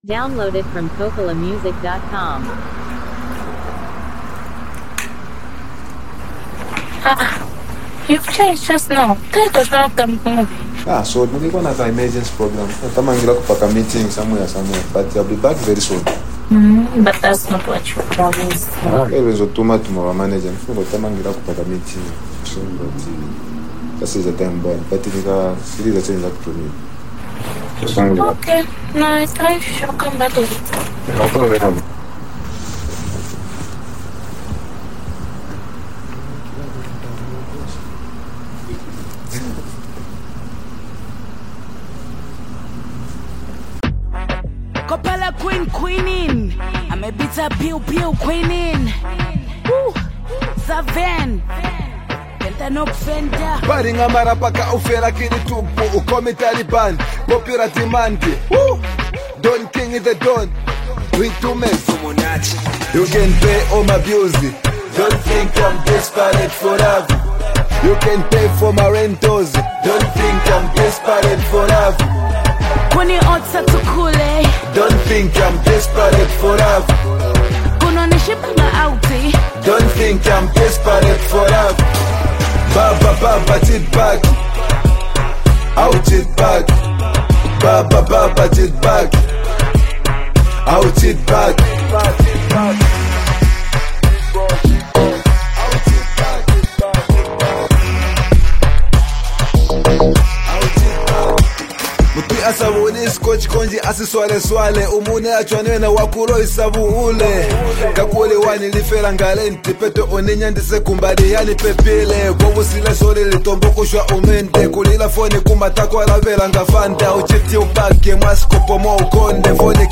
a bold and emotional song